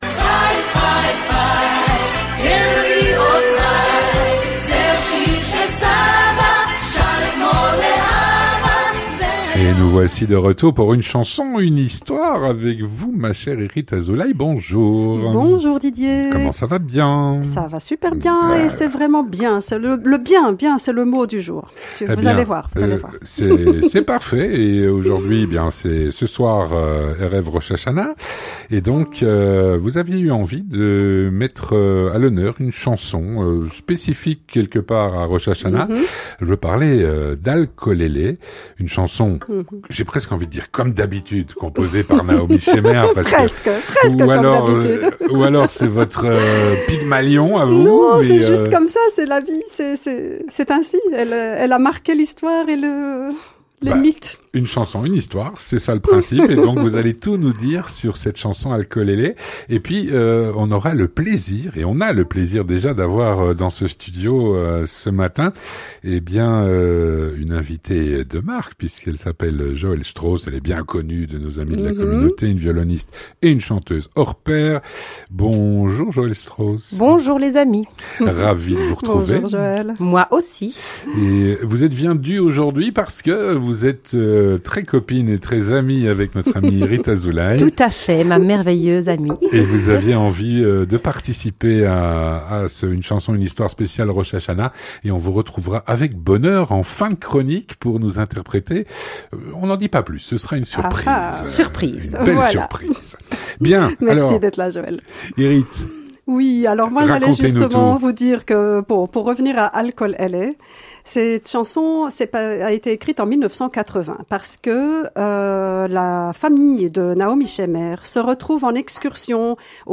Violoniste et chanteuse